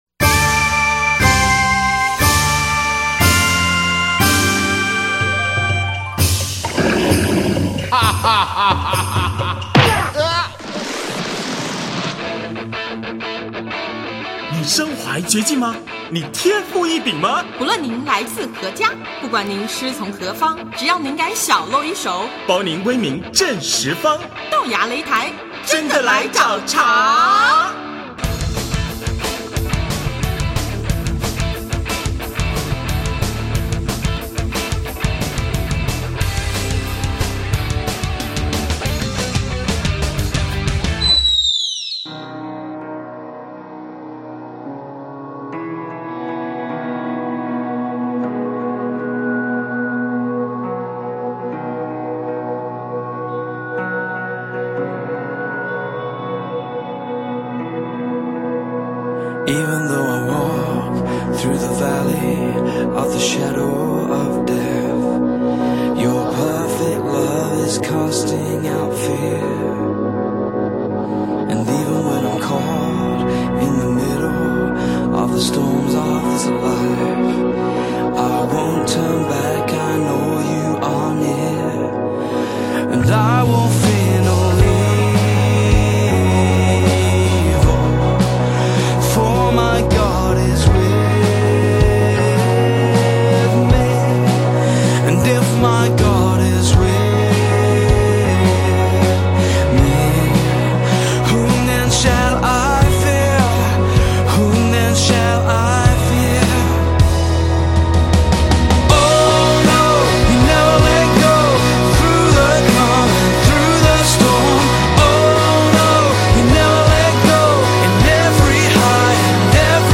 他的乐风很「亚洲」，以五声音阶为主，与中文歌曲的惯用手法相同。